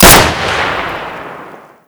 shoot4.ogg